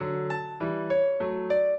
piano
minuet11-10.wav